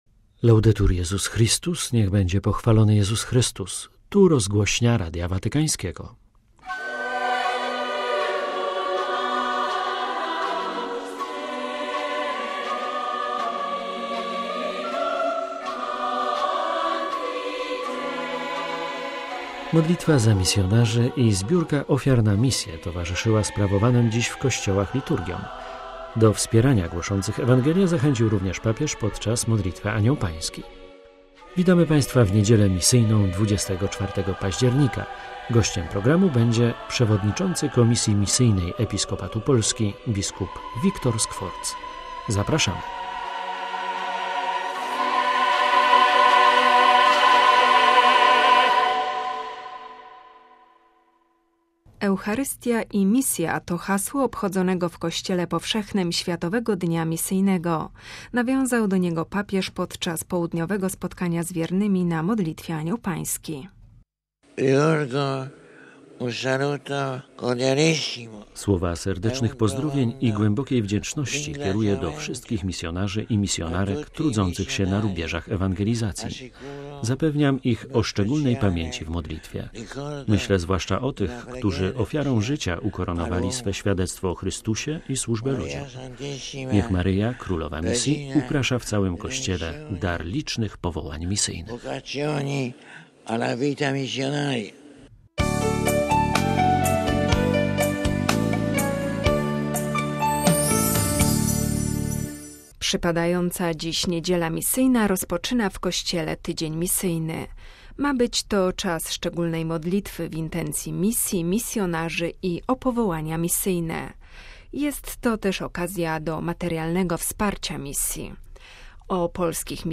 - Relacja z modlitwy Anioł Pański; - Rozmowa z przewodniczącym Komisji Misyjnej Episkopatu Polski, bp. Wiktorem Skworcem; - Fragment Listu Apostolskiego "Mane nobiscum, Domine".